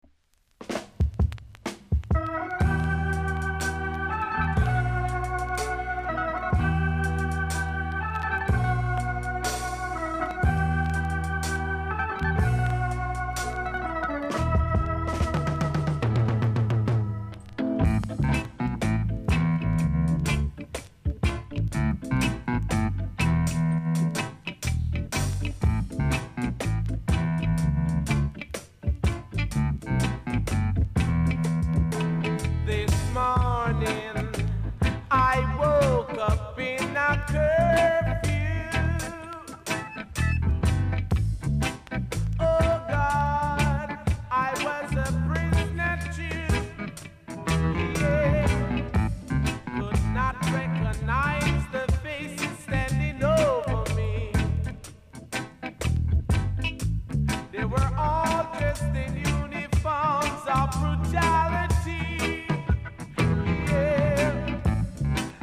※小さなチリ、パチノイズが少しあります。